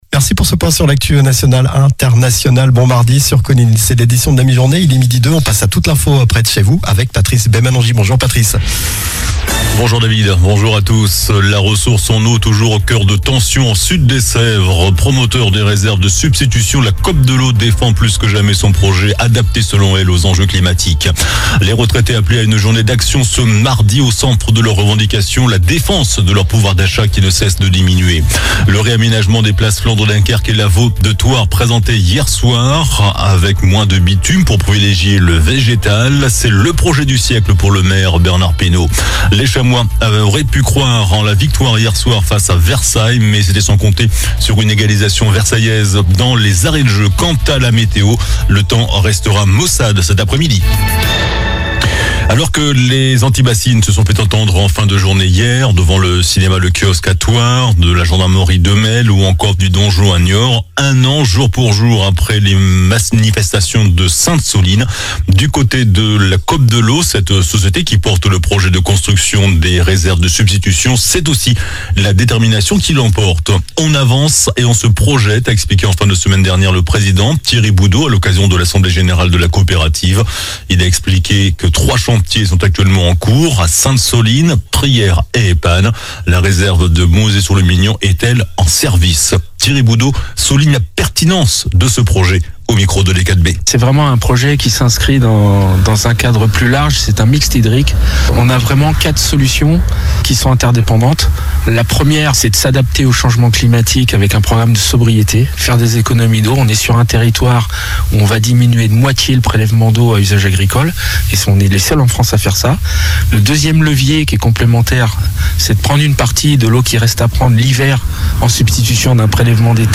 JOURNAL DU MARDI 26 MARS ( MIDI )